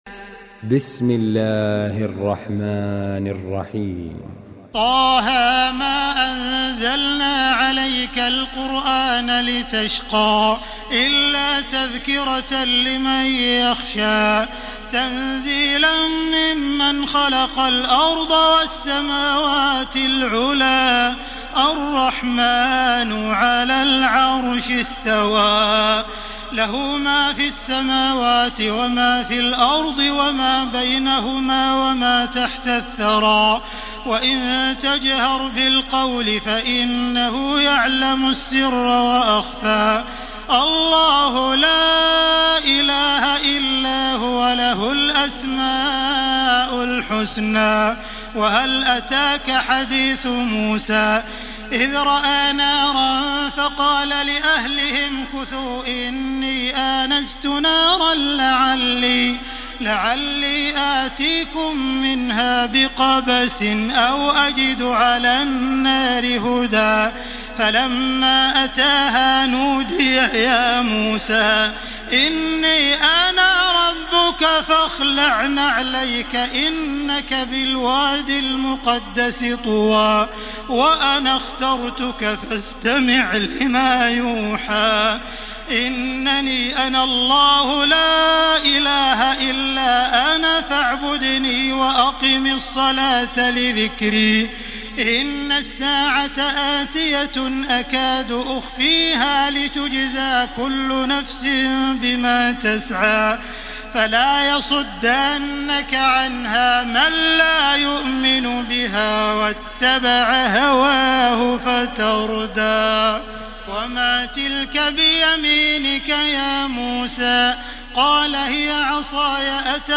تراويح الليلة الخامسة عشر رمضان 1433هـ سورة طه كاملة Taraweeh 15 st night Ramadan 1433H from Surah Taa-Haa > تراويح الحرم المكي عام 1433 🕋 > التراويح - تلاوات الحرمين